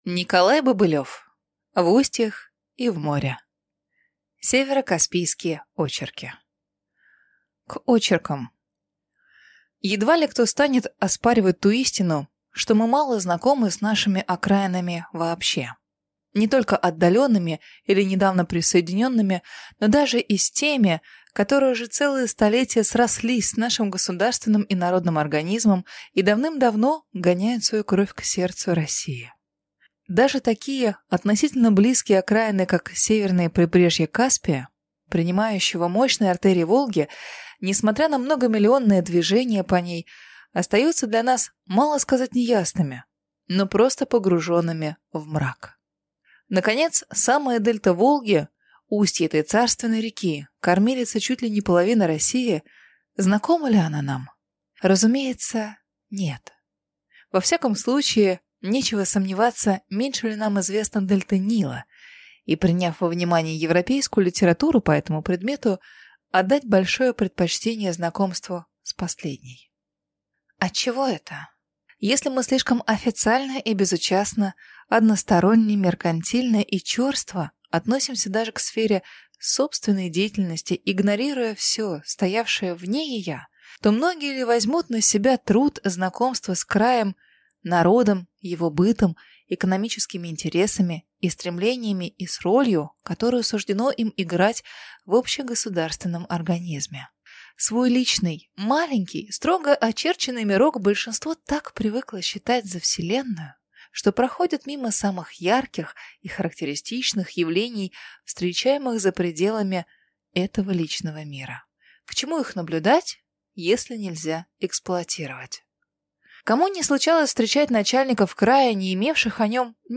Аудиокнига В устьях и в море | Библиотека аудиокниг